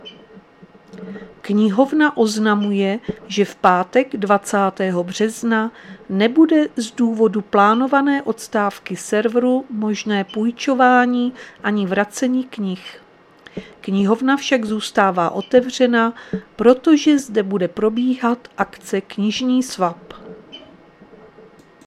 Záznam hlášení místního rozhlasu 19.3.2026
Zařazení: Rozhlas